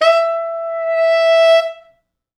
Index of /90_sSampleCDs/East West - Quantum Leap Horns Sax/Quantum Leap Horns Sax/T Sax fts